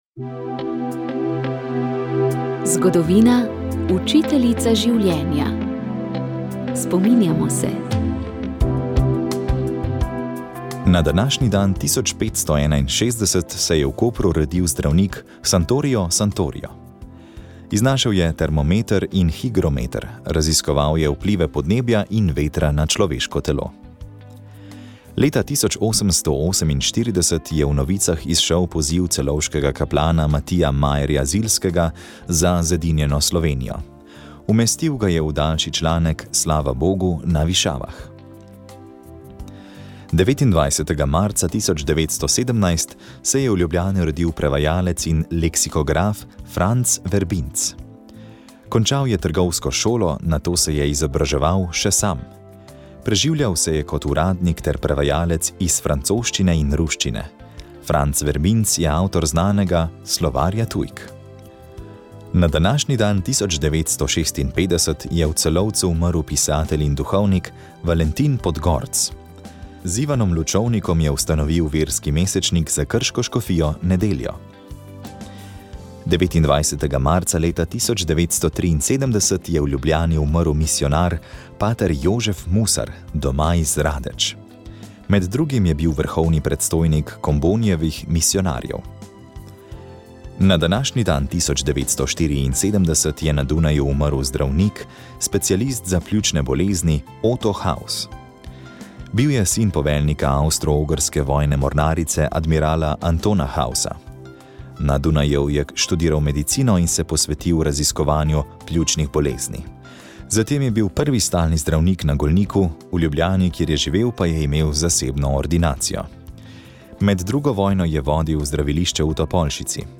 V današnji oddaji smo slišali pričevanje ene od njih.